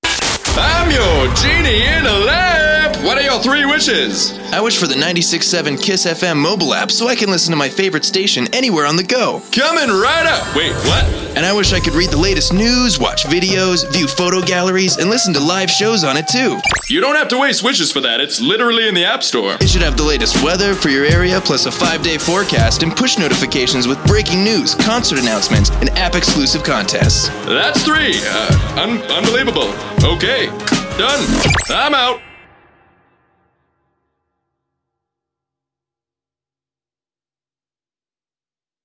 Charismatic male VA with voice smoother than a glass of Pendleton on the rocks.
Radio Sample
Radio Sample.mp3